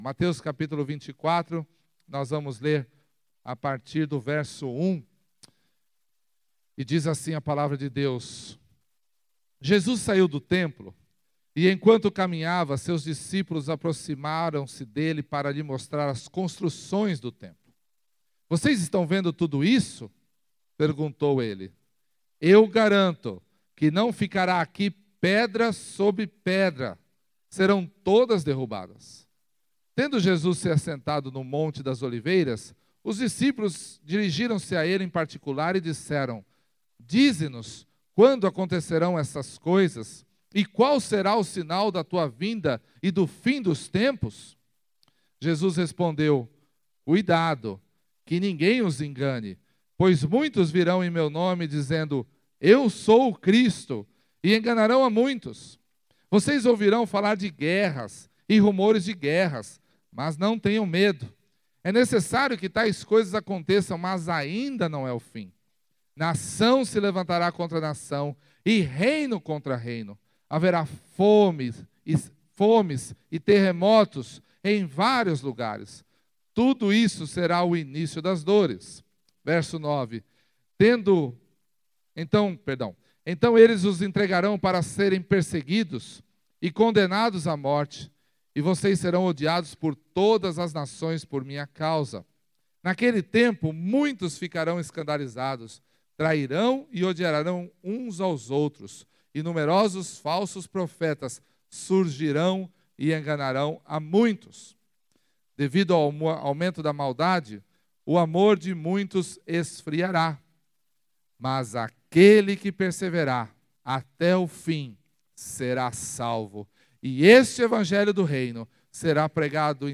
Culto da Conferência Missionária 2019